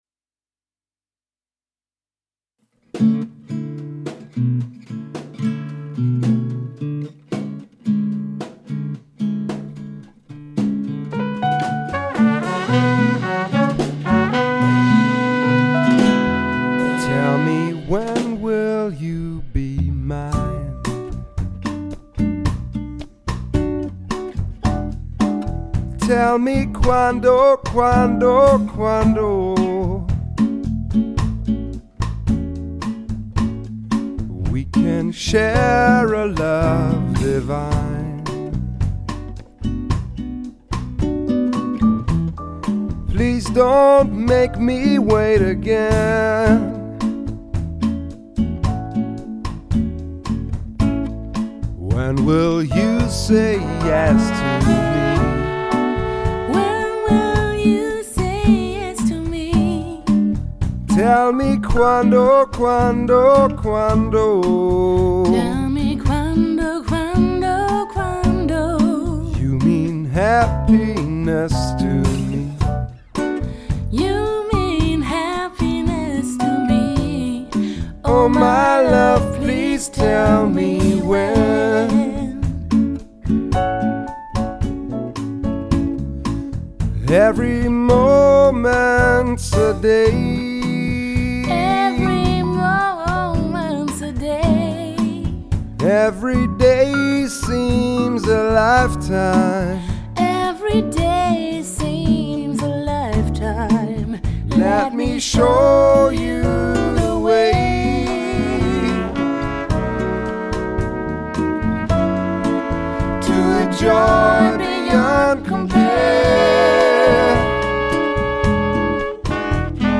100 % Live Musik!